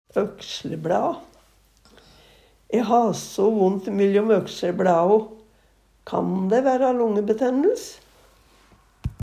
økslebLa - Numedalsmål (en-US)